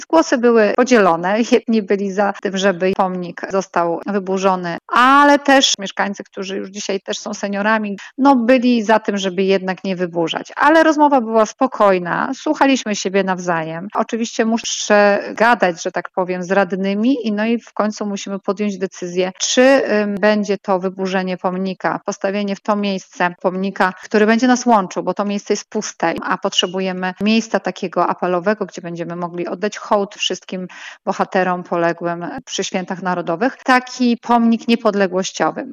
Burmistrz Pyrzyc zabiera głos | Twoje Radio Tylko Przeboje!